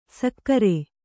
♪ sakkare